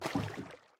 paddle_water7.ogg - 1.21.5
paddle_water7.ogg